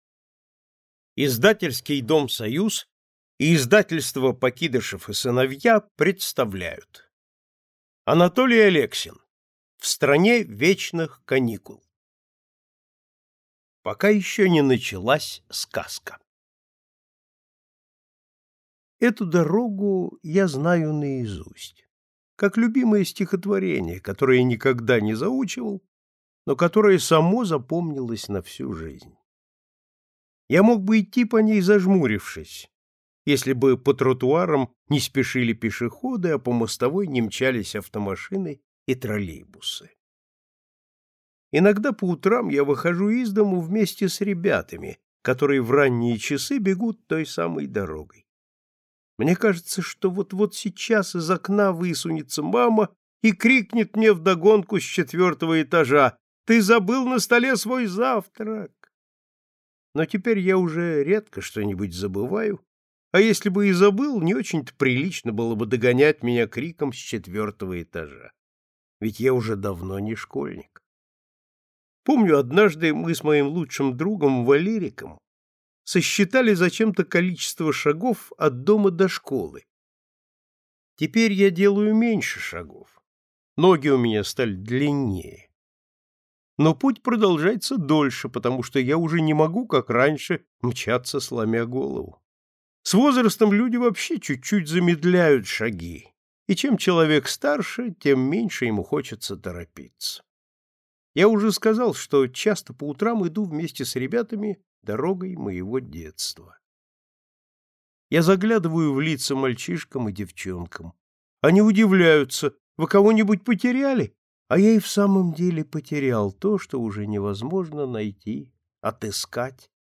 Аудиокнига В стране вечных каникул | Библиотека аудиокниг
Прослушать и бесплатно скачать фрагмент аудиокниги